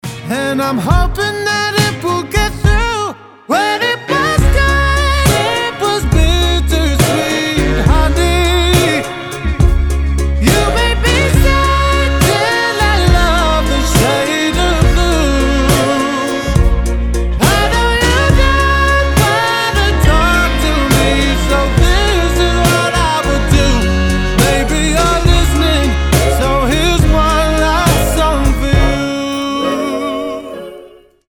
• Качество: 320, Stereo
мужской вокал
Blues